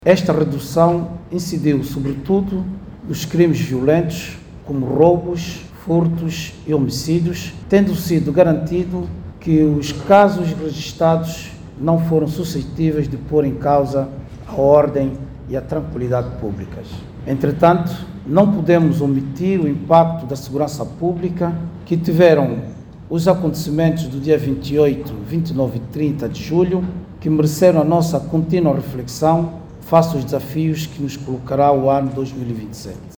Francisco Ribas que falava na abertura do Conselho Superior da Polícia, informou que de Janeiro à Setembro deste ano, as acções da polícia permitiram a redução da criminalidade. O Comissário Geral Francisco Ribas fala numa redução em 67 por cento em relação a 2024.